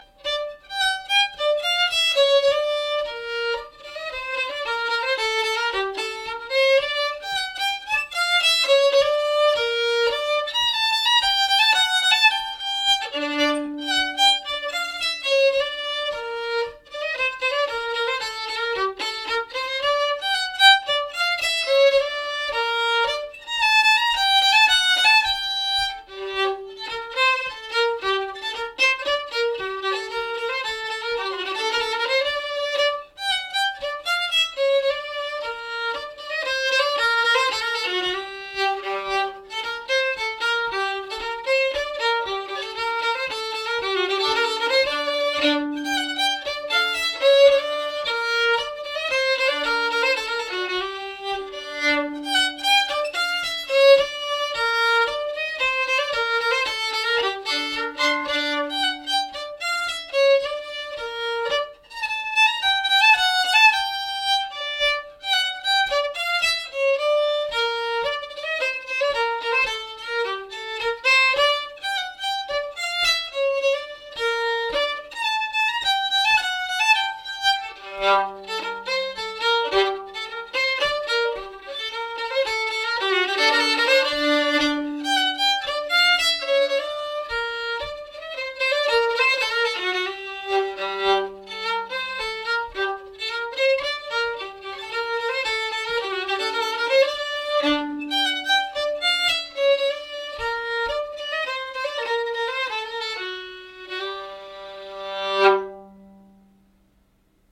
Polska | Lustspel